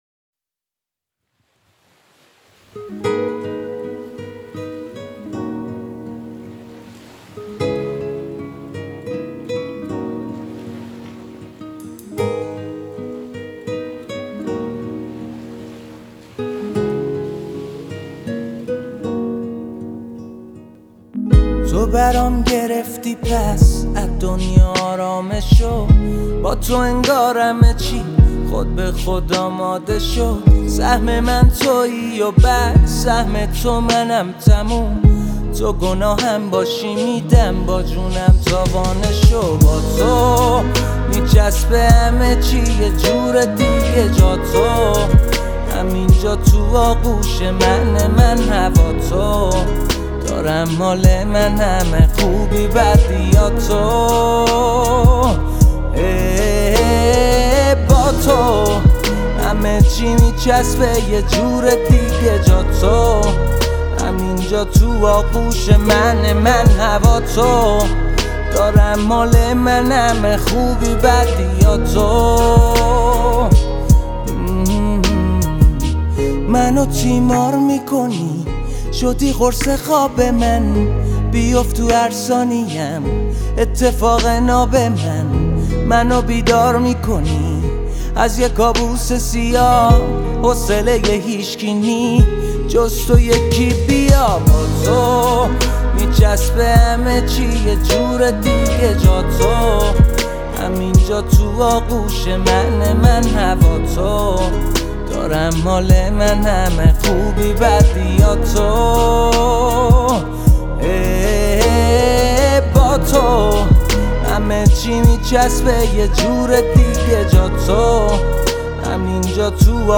یک عاشقانه ساده و احساسی‌ست